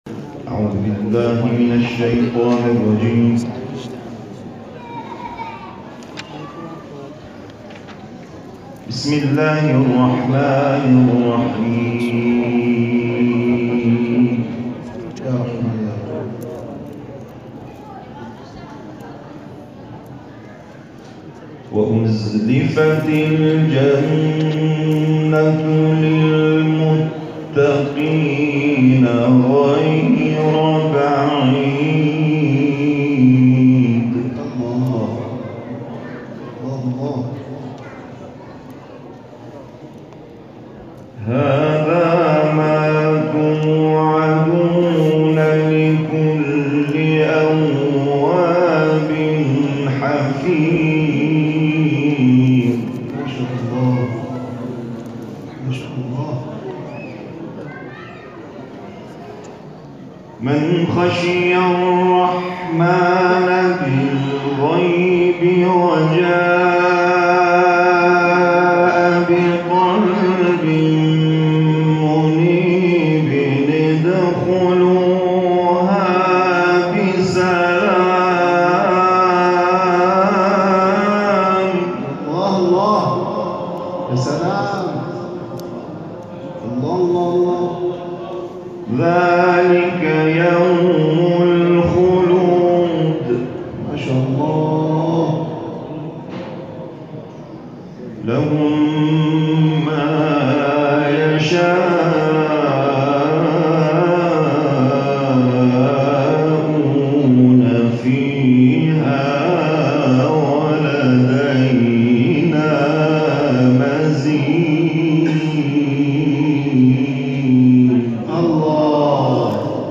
در جوار مضجع شریف سلطان طوس، بعد از نماز مغرب و عشاء برگزار شد.
به تلاوت آیاتی از کلام الله مجید پرداختند